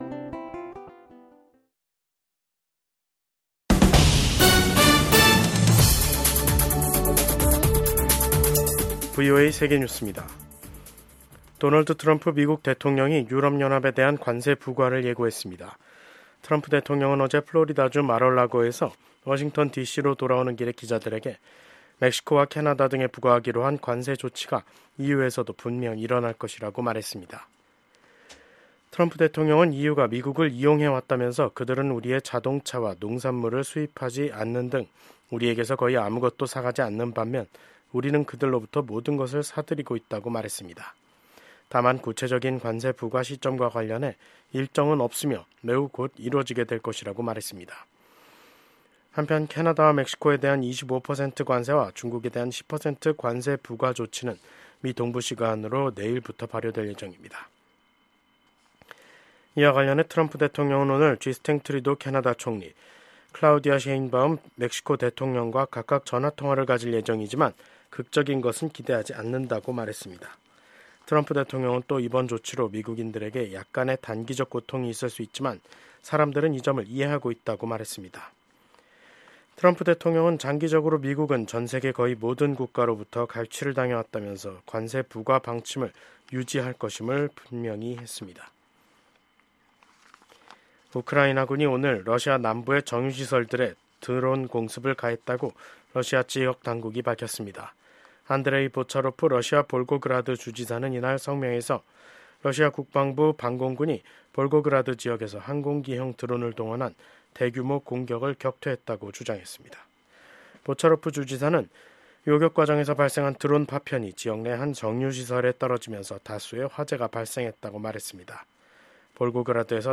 VOA 한국어 간판 뉴스 프로그램 '뉴스 투데이', 2025년 2월 3일 2부 방송입니다. 마르코 루비오 국무장관이 미국이 세계 모든 문제를 짊어지는 현 상황을 비정상적이라고 평가하며 외교 초점을 ‘미국의 이익’에 맞출 것이라고 밝혔습니다. 루비오 국무장관이 불량국가라고 언급한 것에 대한 반응으로 북한은 도널드 트럼프 행정부 출범 이후 첫 대미 비난 담화를 냈습니다.